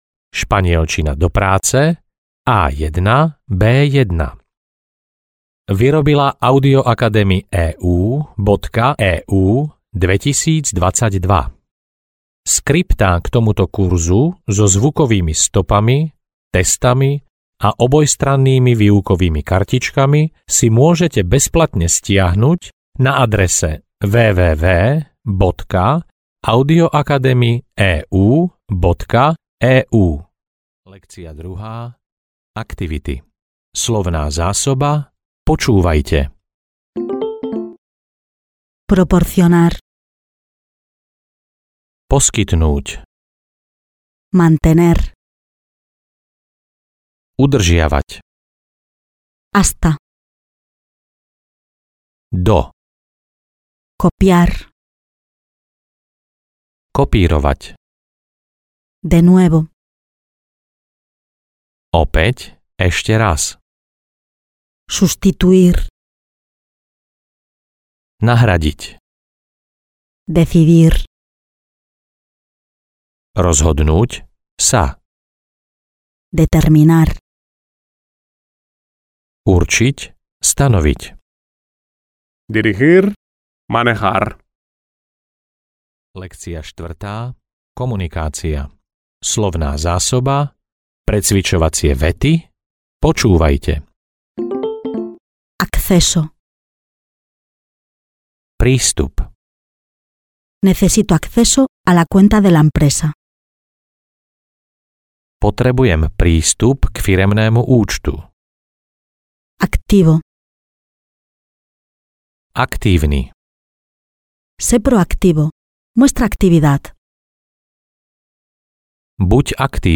Obchodná španielčina A1-B1 audiokniha
Ukázka z knihy